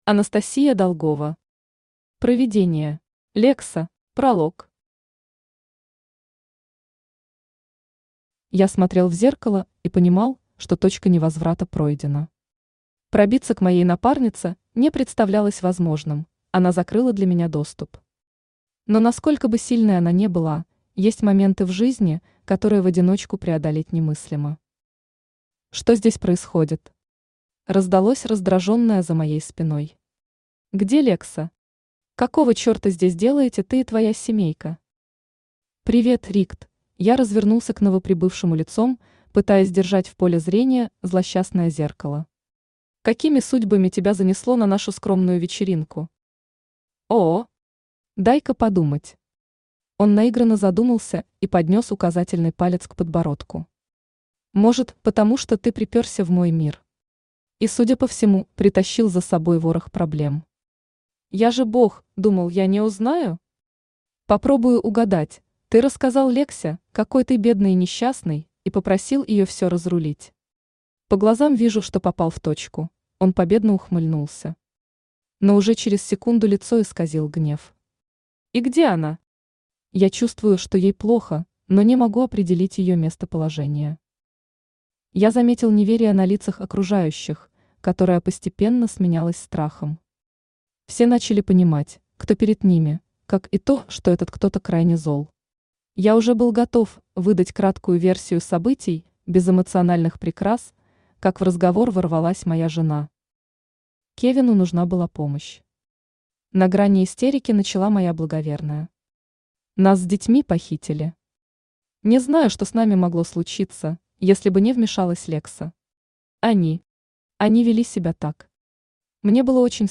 Аудиокнига Провидение. Лекса | Библиотека аудиокниг
Лекса Автор Анастасия Долгова Читает аудиокнигу Авточтец ЛитРес.